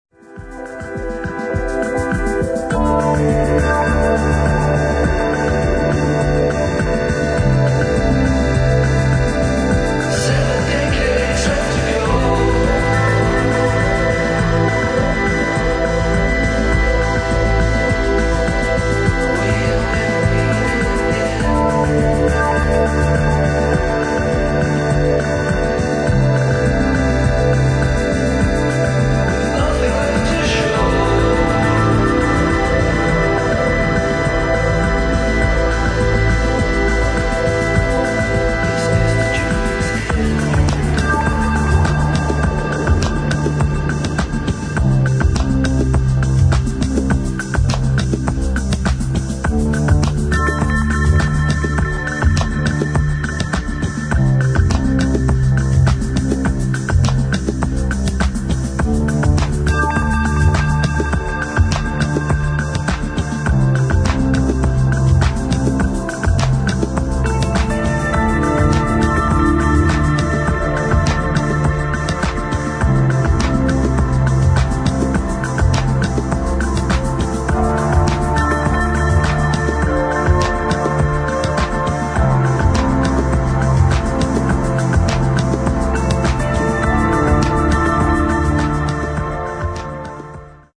[ HOUSE / ELECTRONIC ]